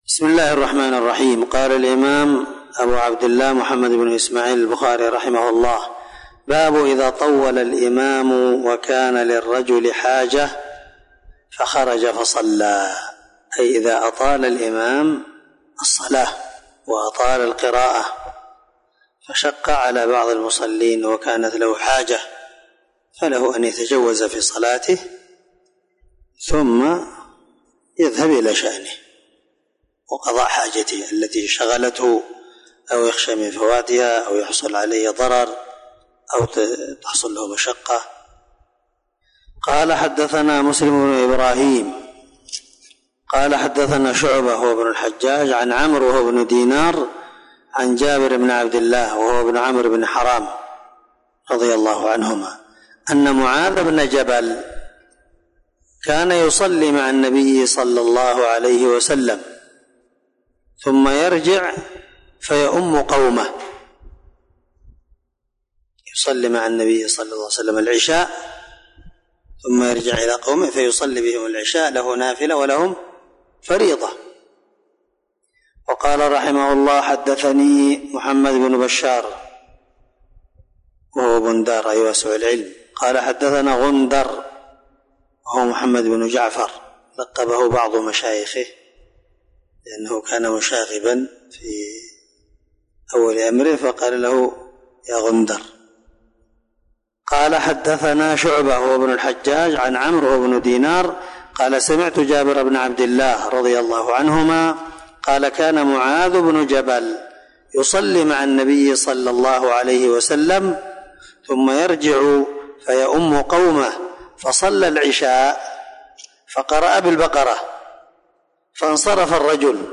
471الدرس 54من شرح كتابالأذان حديث رقم (700_701) من صحيح البخاري